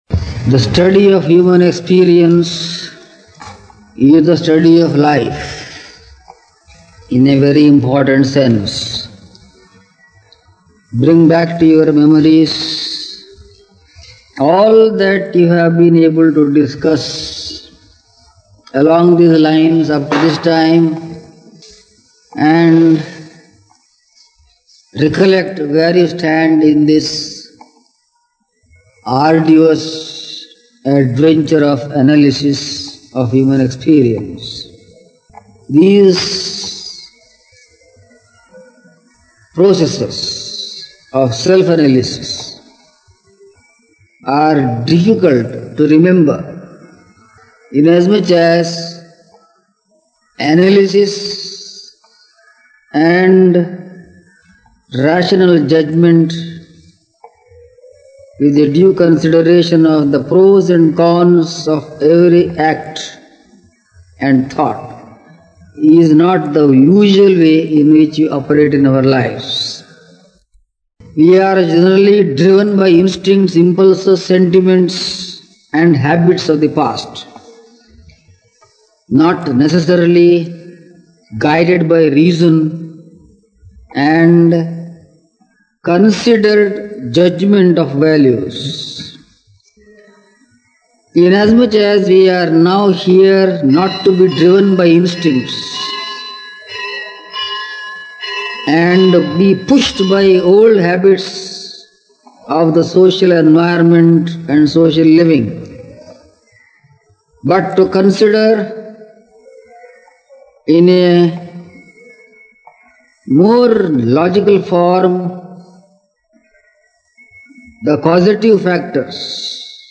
Lectures on epistemology, the philosophical theory of knowledge